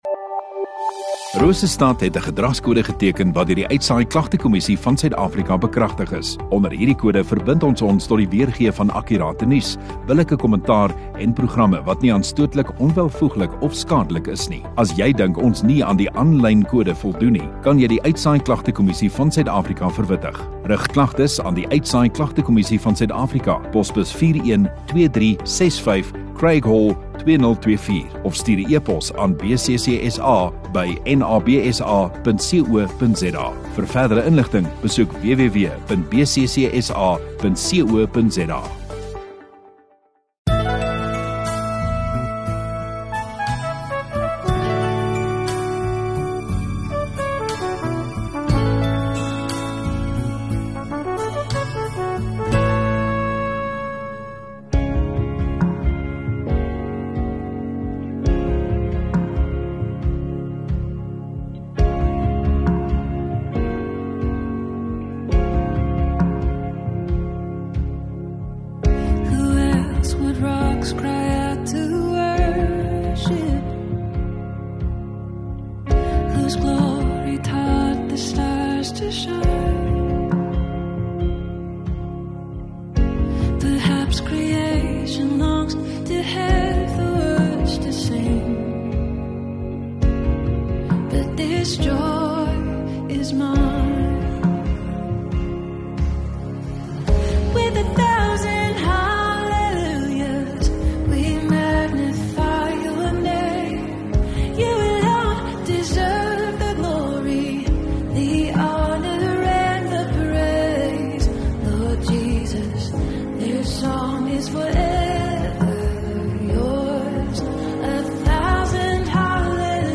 9 Nov Saterdag Oggenddiens